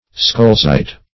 skolezite - definition of skolezite - synonyms, pronunciation, spelling from Free Dictionary
Skolezite \Skol"e*zite\